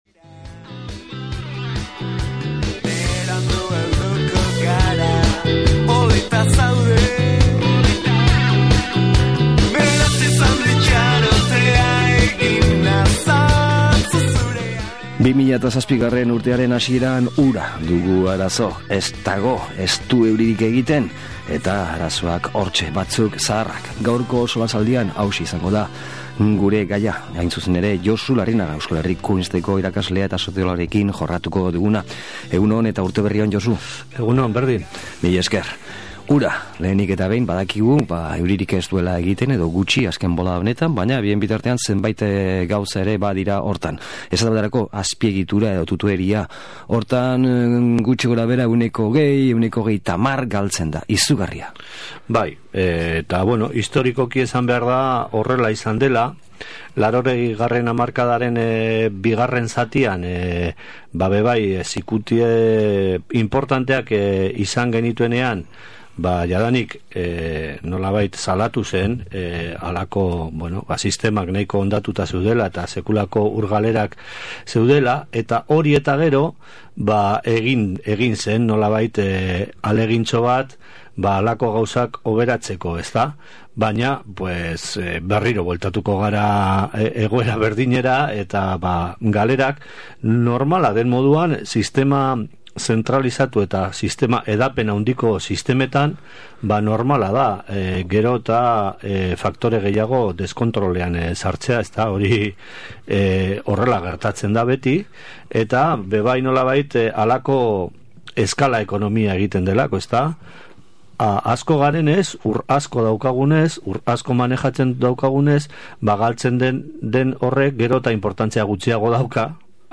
SOLASALDIA: Uraren arazoak.